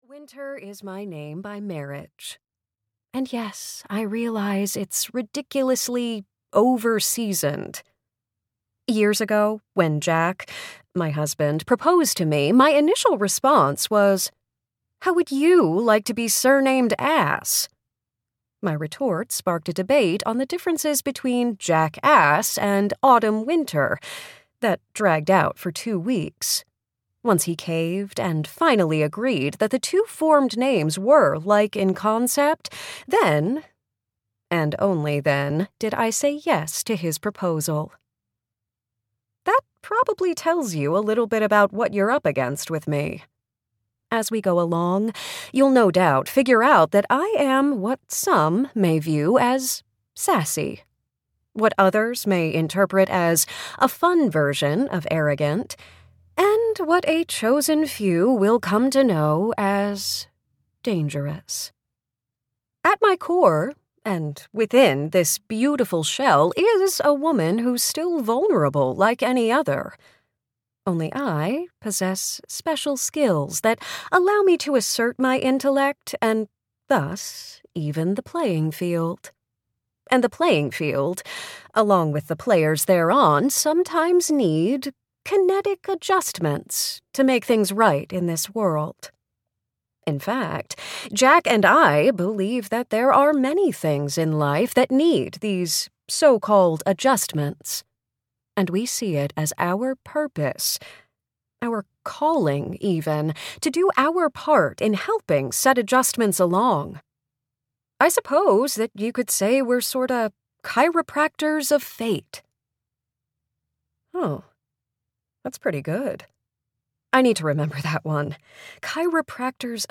Error Code (EN) audiokniha
Ukázka z knihy